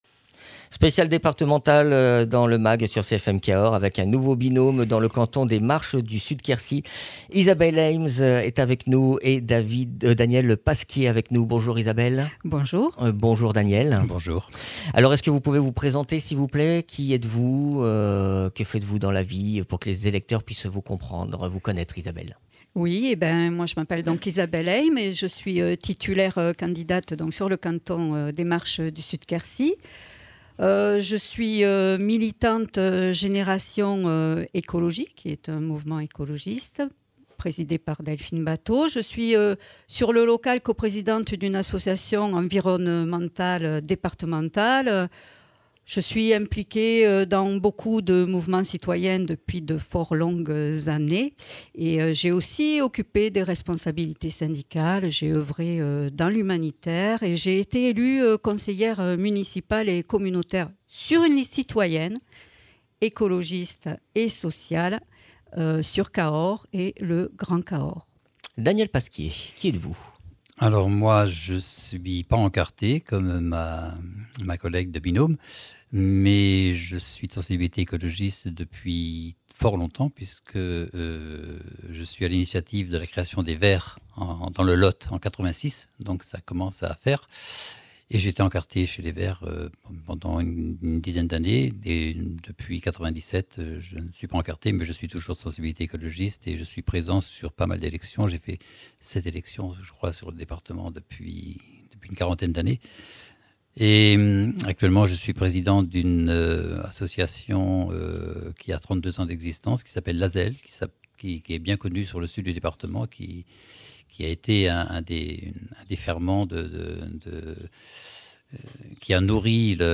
Interview des candidats écologistes
Interviews